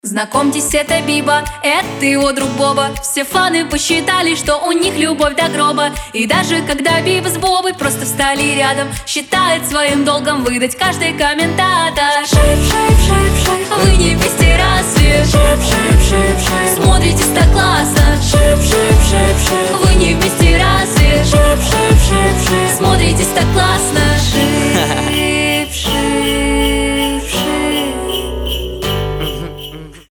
гитара
веселые
дуэт
колокольчики
смешные